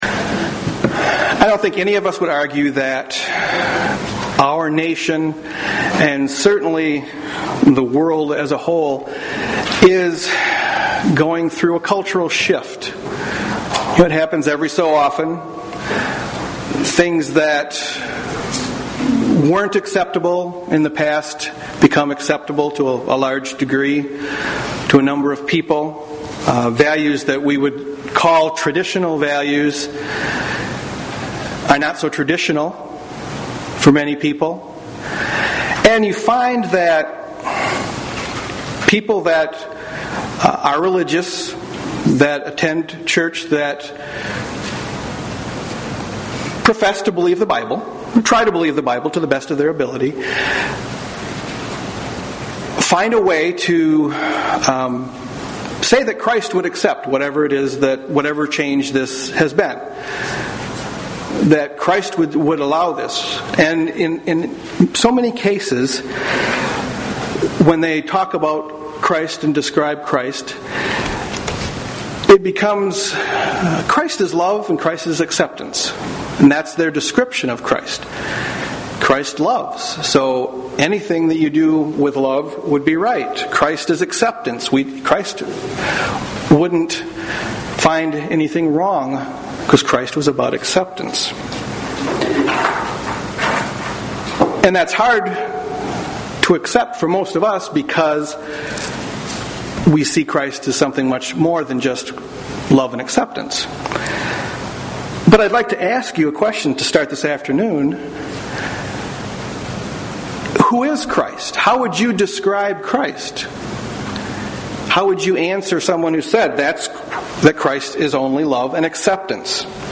UCG Sermon Studying the bible?
Given in Grand Rapids, MI